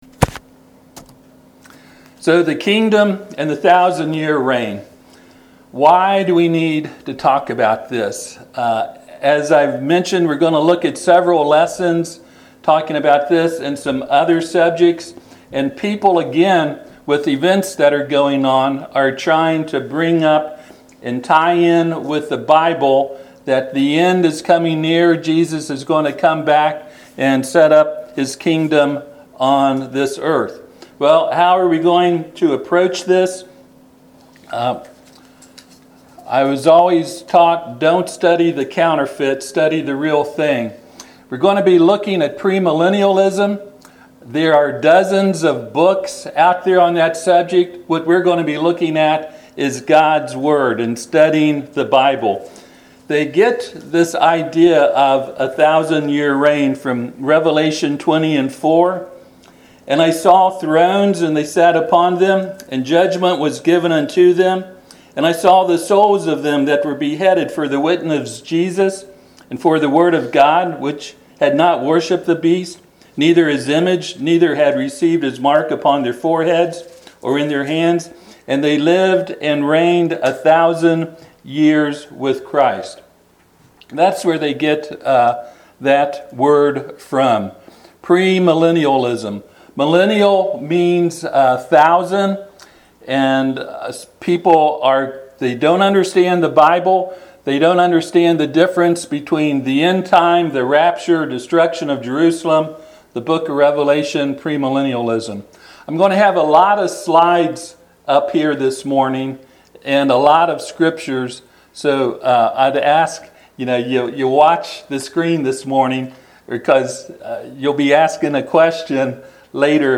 Revelation 20:4 Service Type: Sunday AM Topics: destruction of Jerusalem , kingdom , premillennialism , rapture « Who Is God?